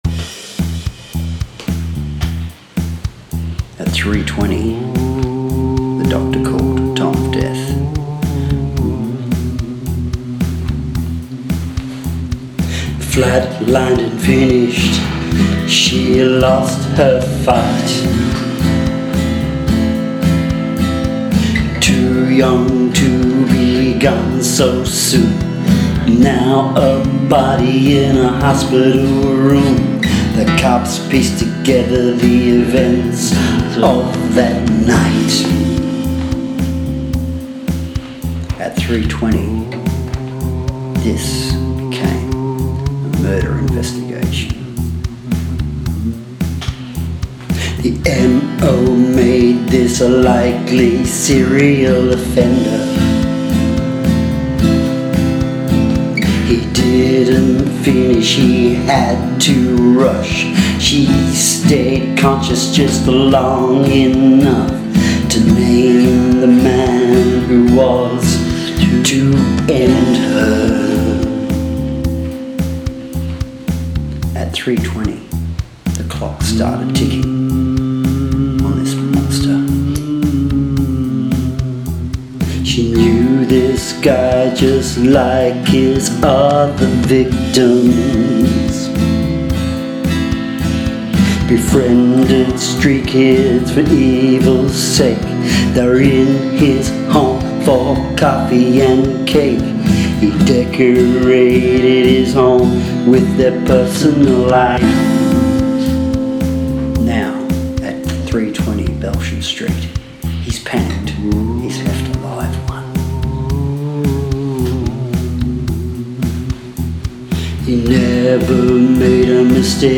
Ambience
Just some ropey singing dragging it down the rankings.